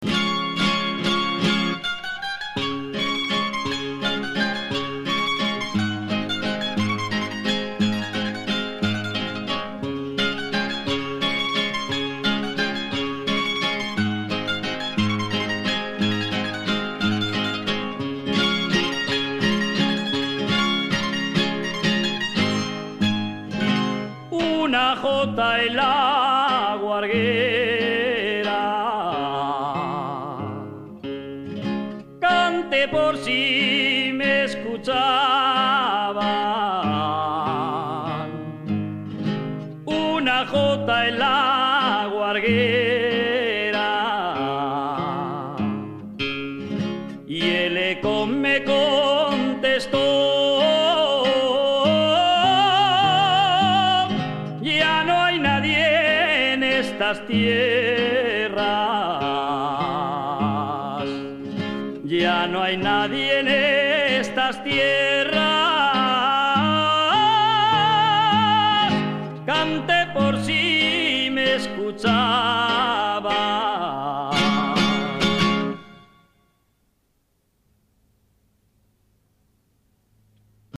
jota_guarguera.mp3